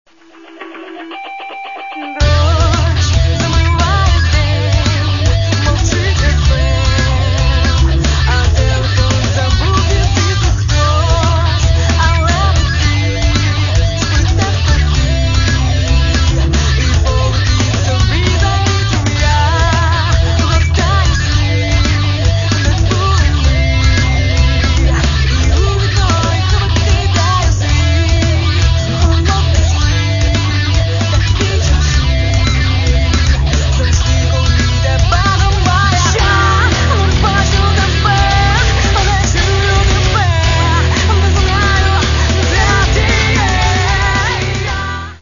Catalogue -> Rock & Alternative -> Energy Rock